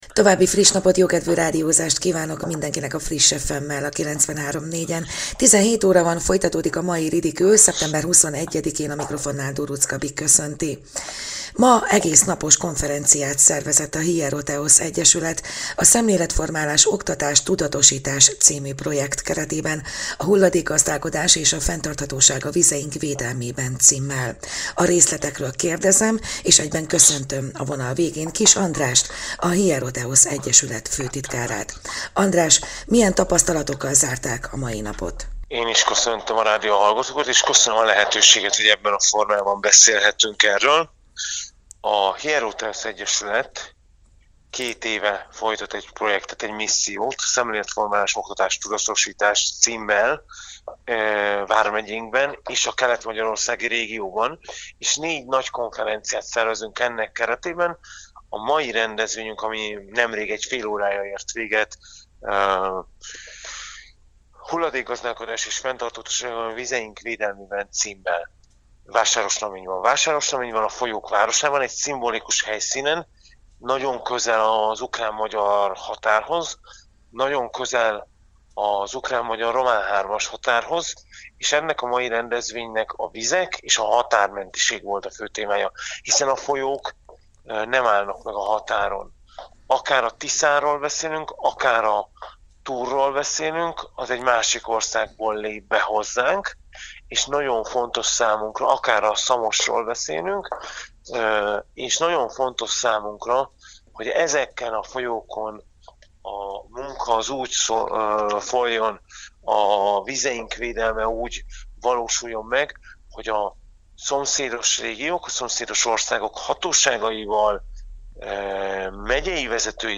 Rádió
2023.szeptember 21.: Vásárosnamény - Hulladékgazdálkodás és fenntarthatóság a vizeink védelmében - interjú
interju_vasarosnameny.mp3